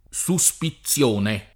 SuSpiZZL1ne], ben rappresentate nei trecentisti, tutte col sign. di «sospetto» in generale